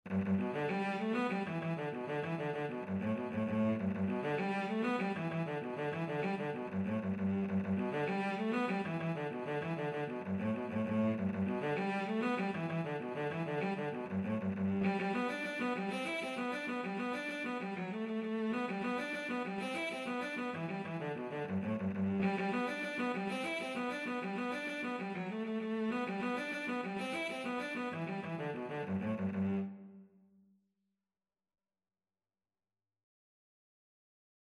Cello version
Traditional Music of unknown author.
G major (Sounding Pitch) (View more G major Music for Cello )
6/8 (View more 6/8 Music)
G3-E5
Cello  (View more Easy Cello Music)
Traditional (View more Traditional Cello Music)